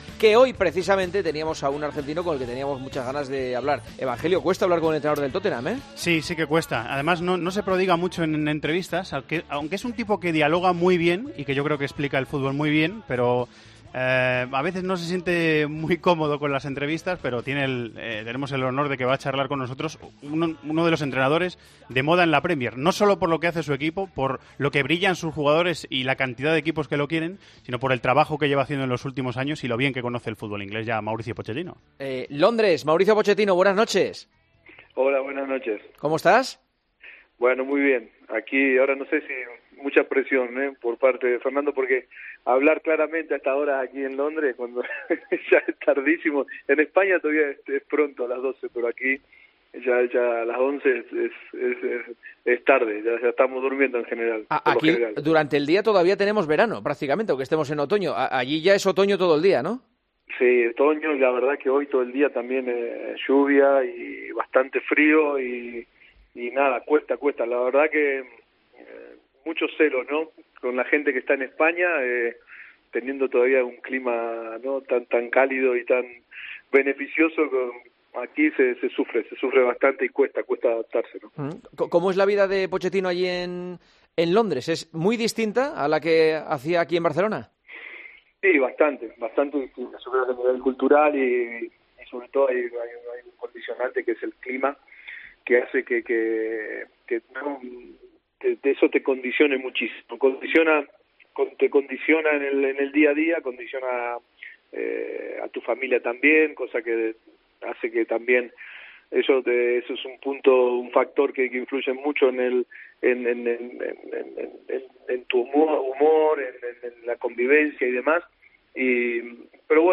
Este miércoles, Juanma Castaño charló en El Partidazo de COPE con el entrenador de moda en la Premier que, además, se 'mojó' con el problema...